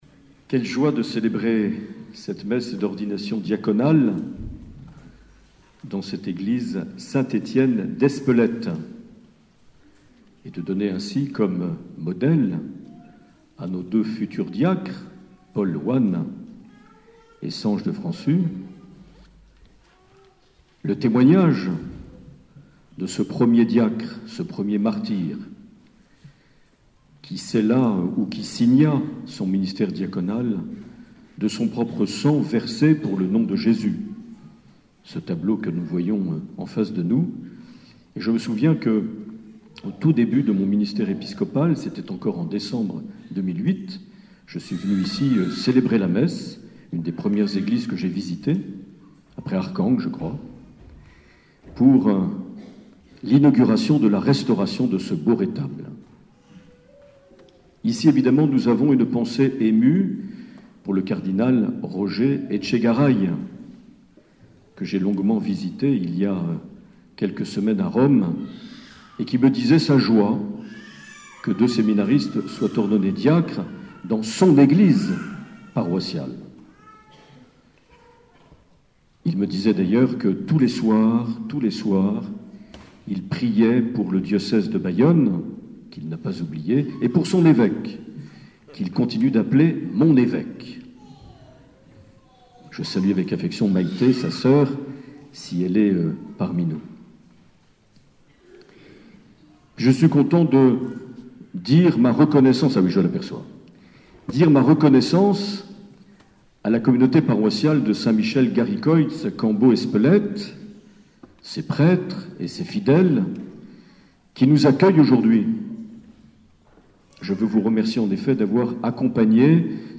14 mai 2015 - Espelette - Ordinations Diaconales
Les Homélies
Une émission présentée par Monseigneur Marc Aillet